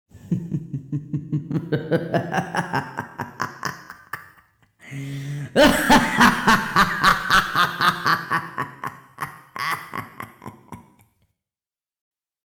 Звуки со смехом злого ученого, маньяка и его лаборатория для монтажа видео в mp3 формате.